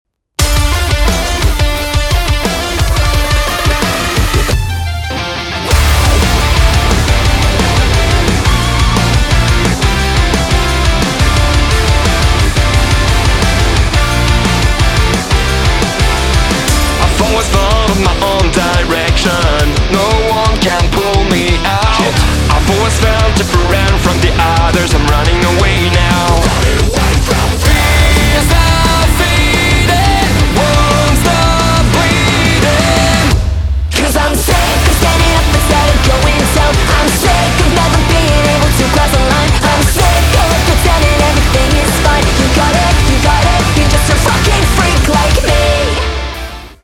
• Качество: 320, Stereo
громкие
Драйвовые
электрогитара
Alternative Rock
Драйвовый альтернативный рок